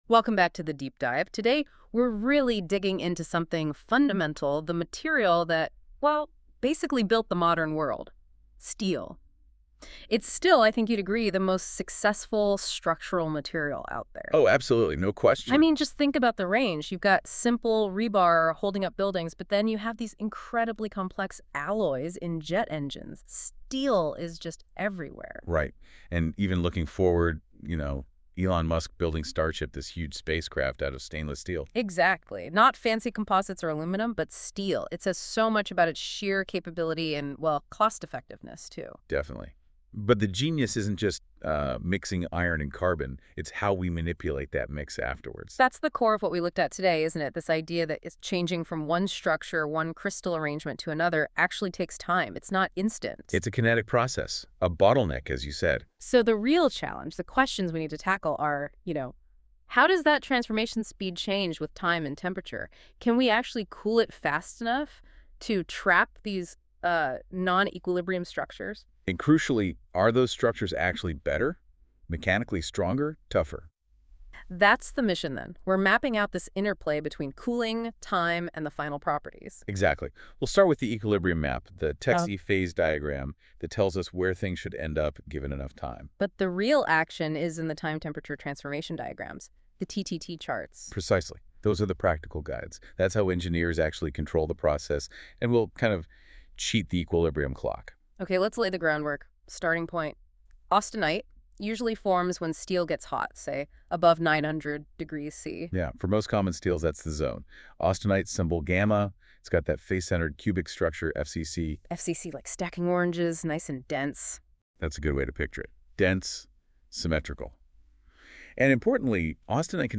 Video: the shape of cold Conversation about Chapter 8, Fe-C. Video: the ultimate transformer Conversation about Chapter 9, precipitation.